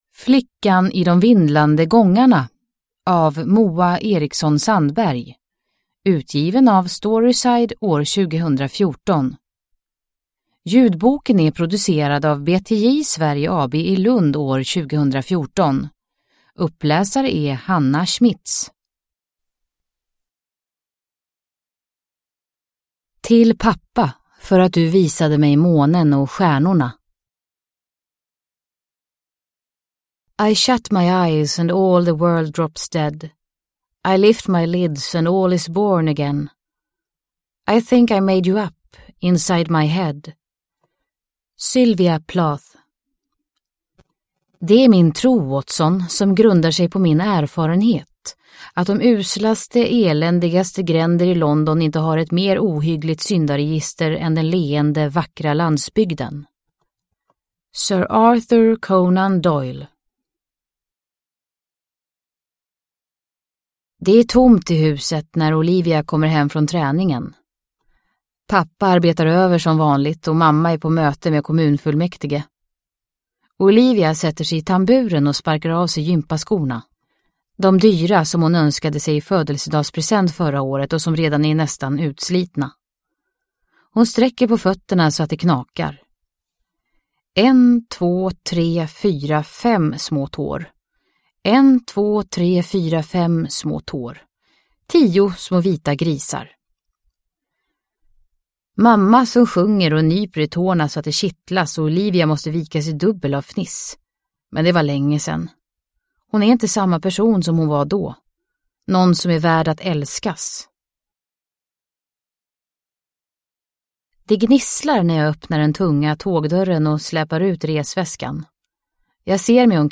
Flickan i de vindlande gångarna – Ljudbok – Laddas ner